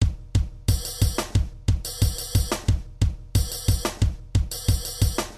描述：Need a dark piano
标签： 84 bpm Rap Loops Drum Loops 927.19 KB wav Key : Unknown
声道立体声